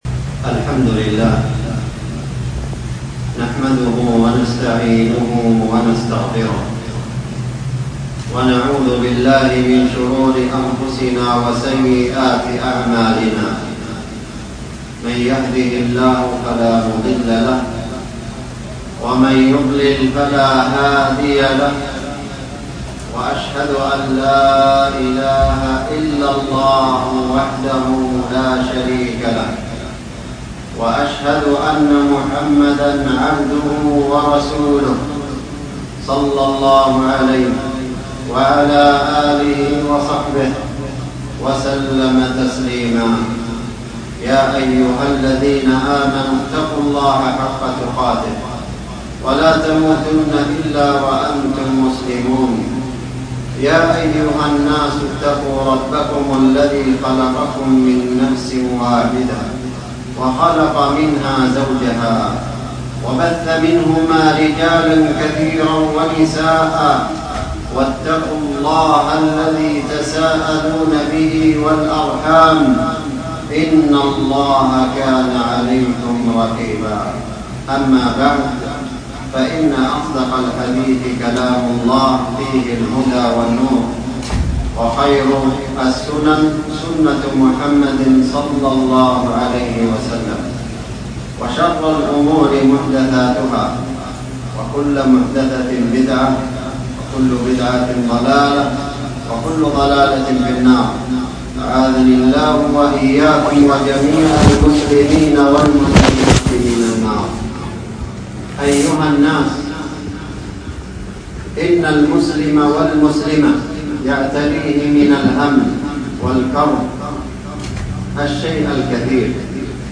خطبة جمعة حول تقوى الله عز وجل ، وأهميتة التقوى ، وحال المتقين .